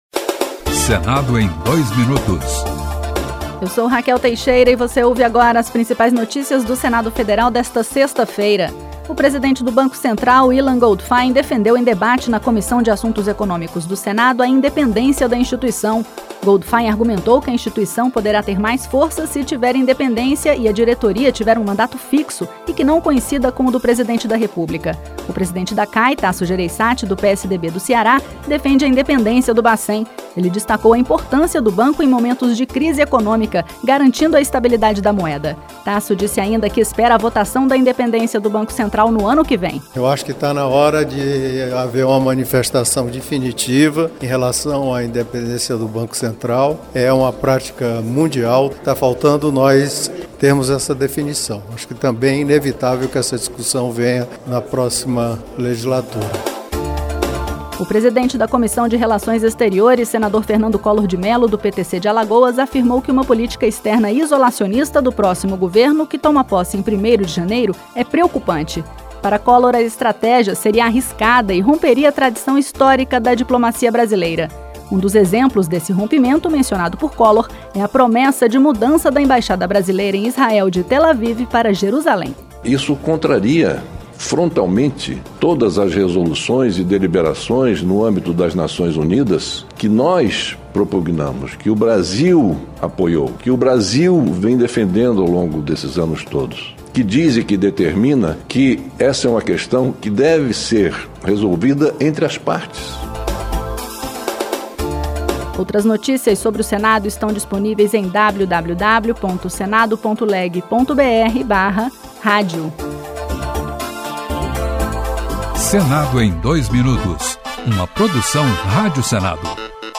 Um resumo das principais notícias do Senado